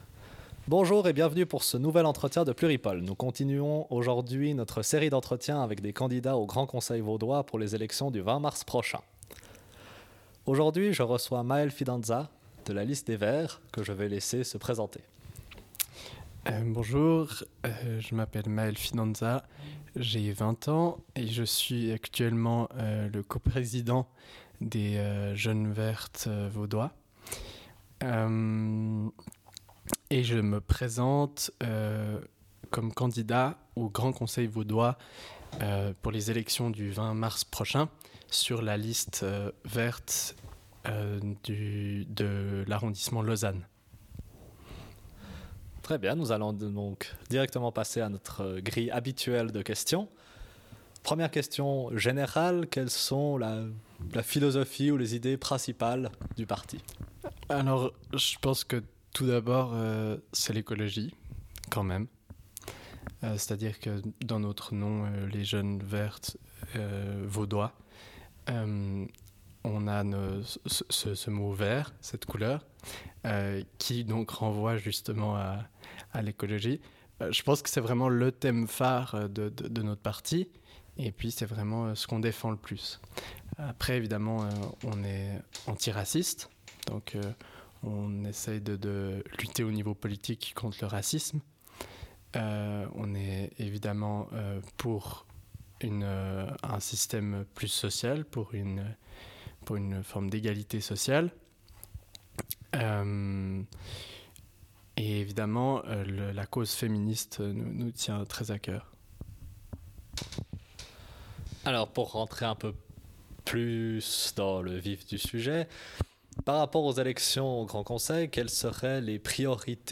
[ENTRETIEN] Élection du Grand Conseil vaudois